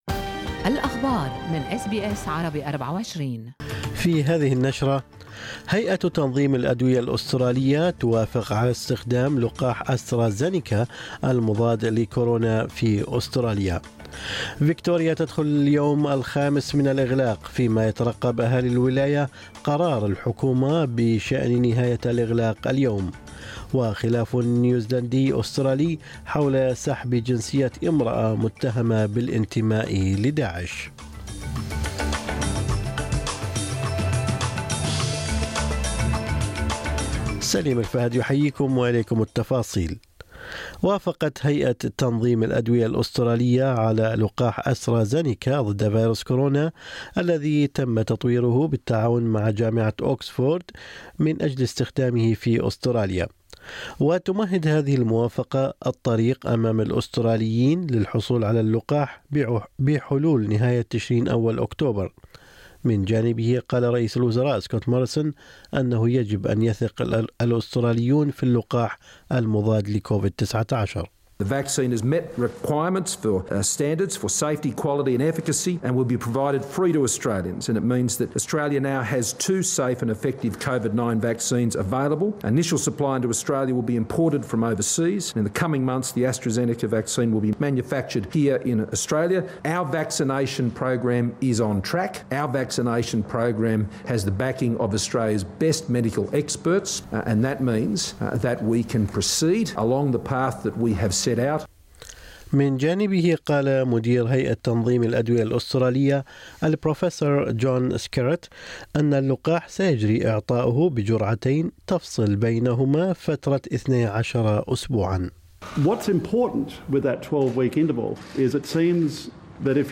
نشرة أخبار الصباح 21/2/2021